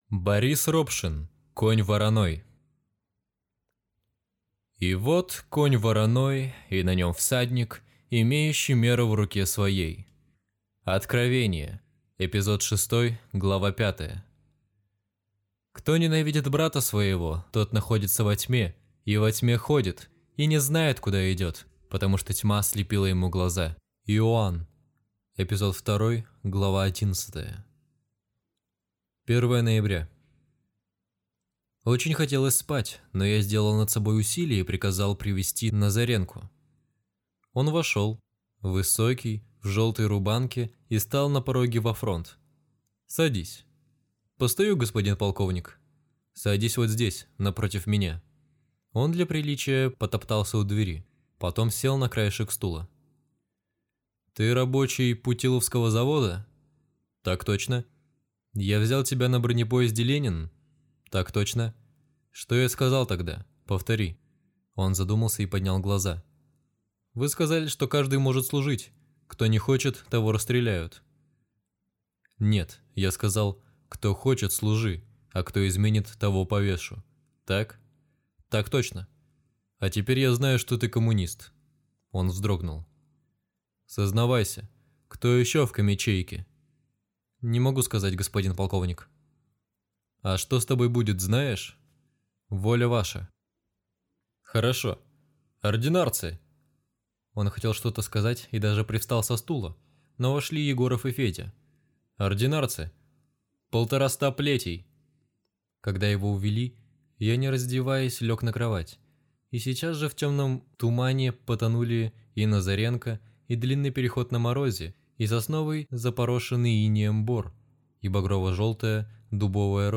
Аудиокнига Конь вороной | Библиотека аудиокниг